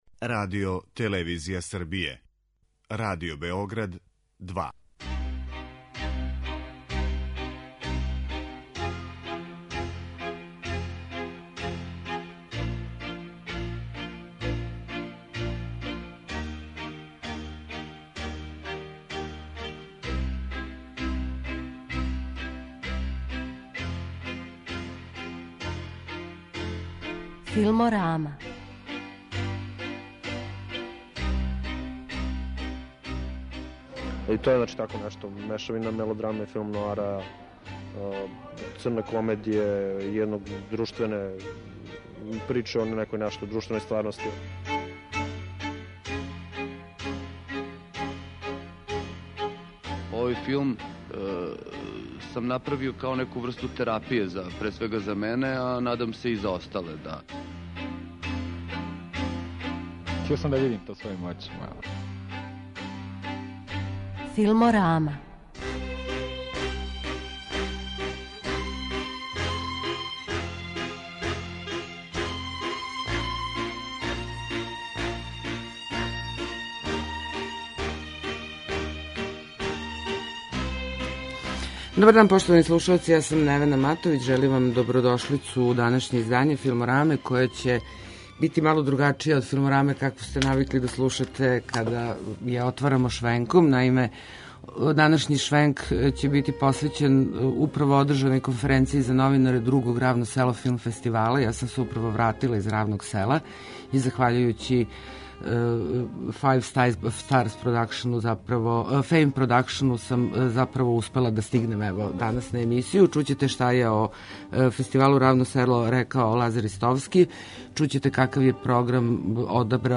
Емисија о филму